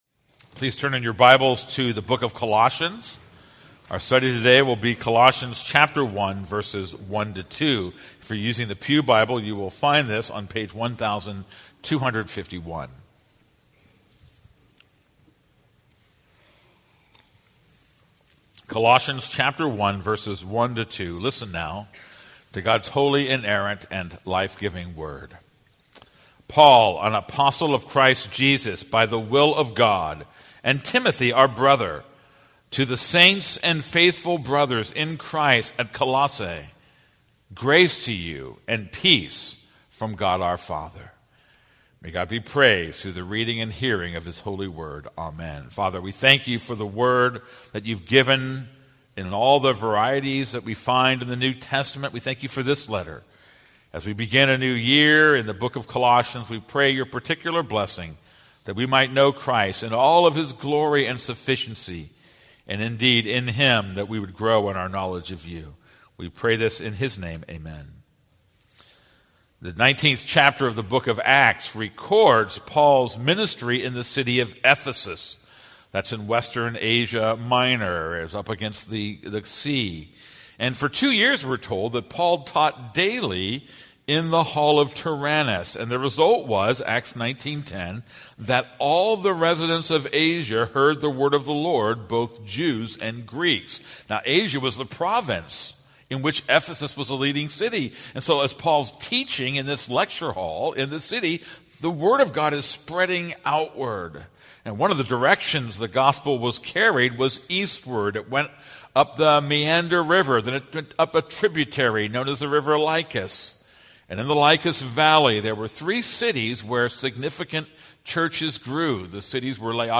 This is a sermon on Colossians 1:1-2.